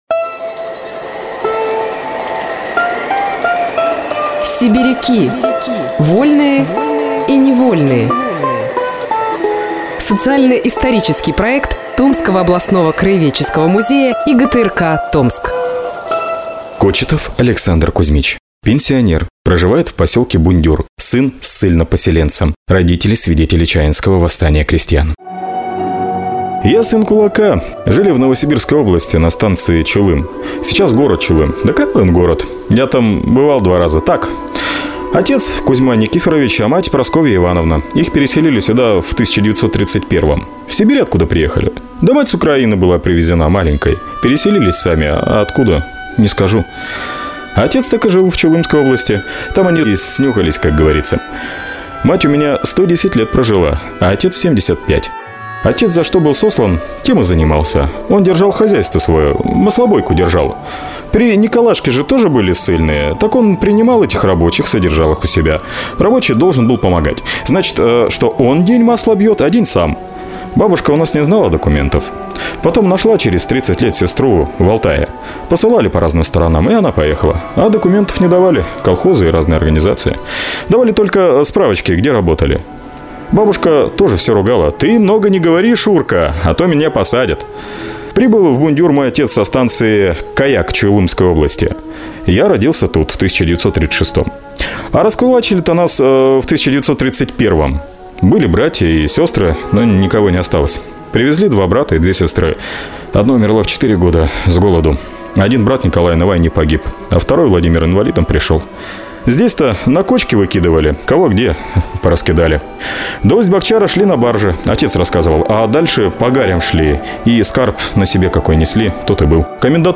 Мужской голос